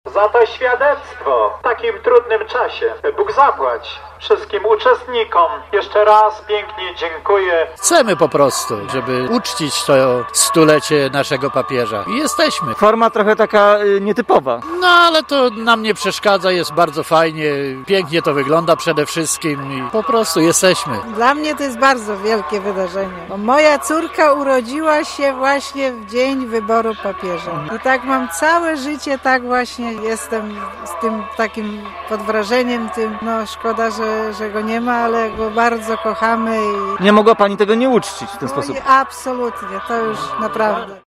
Biskup Tadeusz Lityński bardzo dziękował organizatorom uroczystości za takie uczczenie Naszego rodaka.